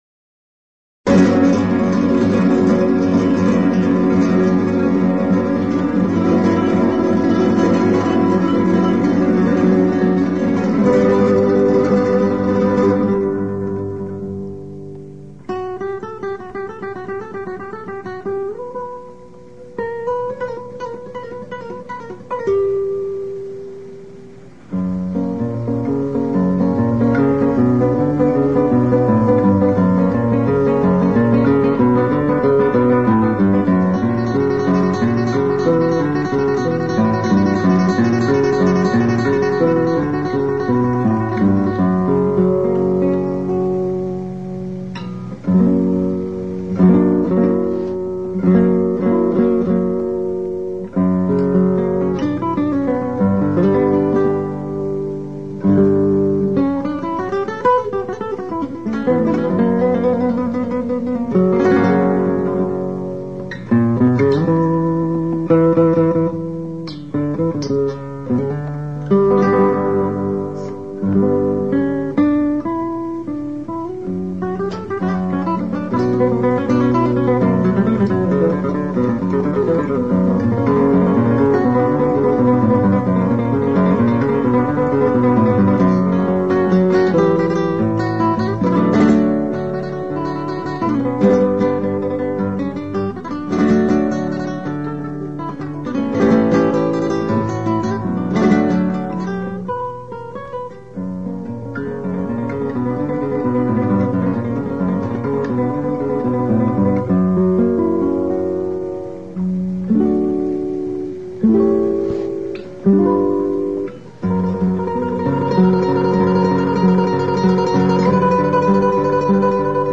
Kresge Little Theater MIT Cambridge, Massachusetts USA